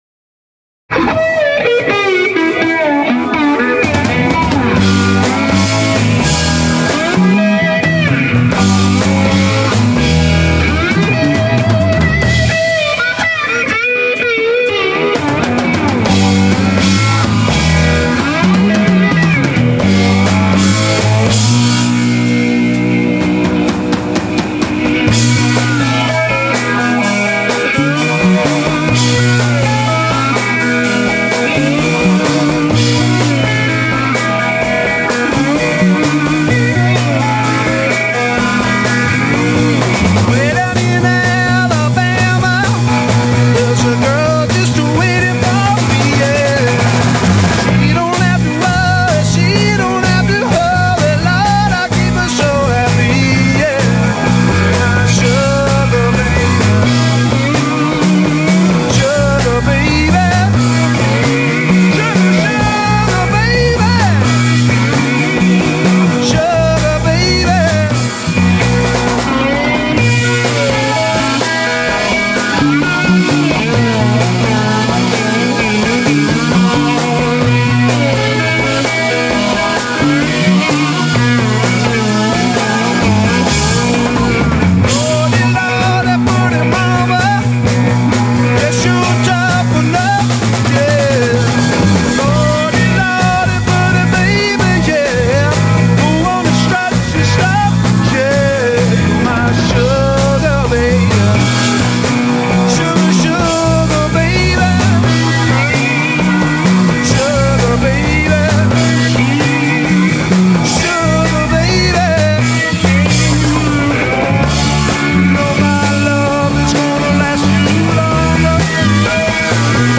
Genre : Rock
Live - Columbus, OH, December 8, 1973